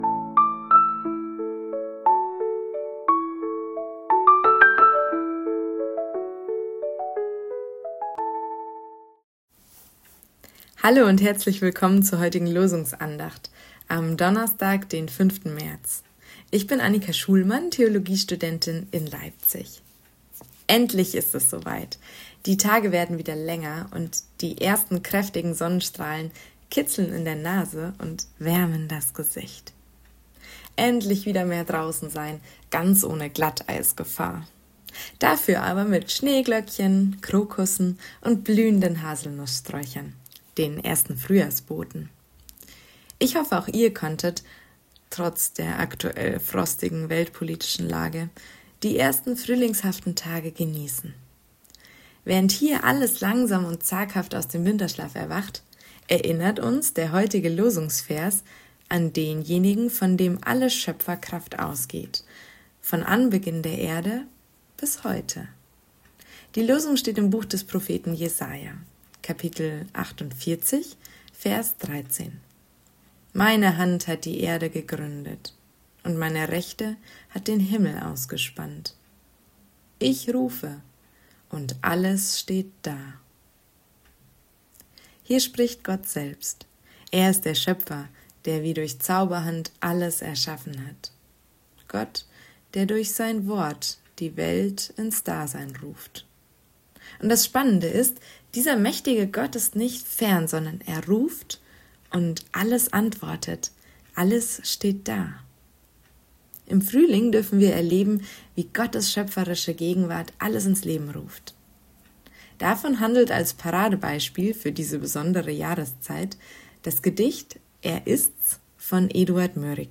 Losungsandachten
Text und Sprecherin